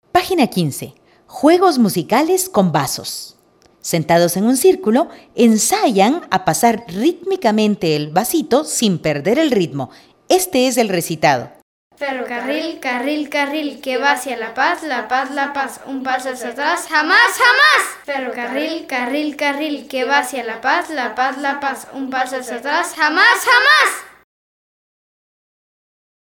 Juego con vasos